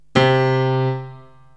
PIANO 0009.wav